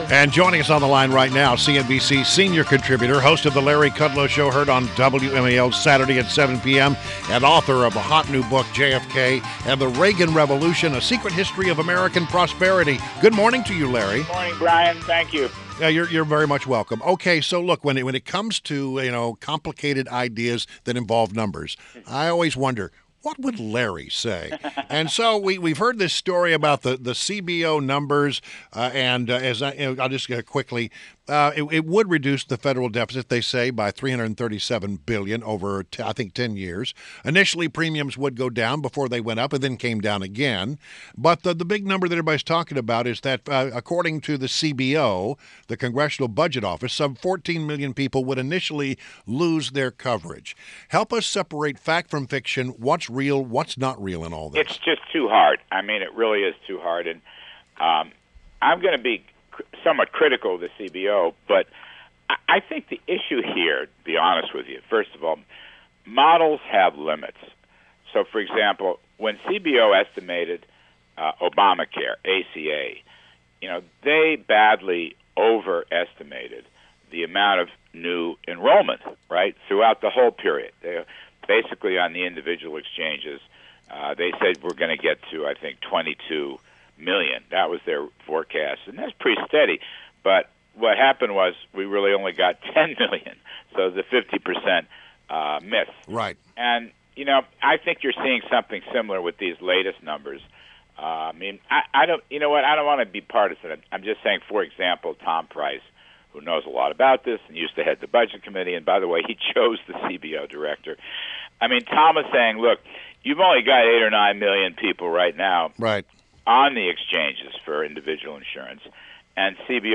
WMAL Interview - LARRY KUDLOW - 03.14.17